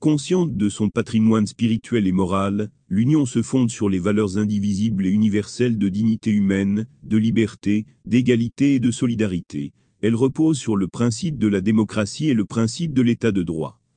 Our Voice Over Portfolio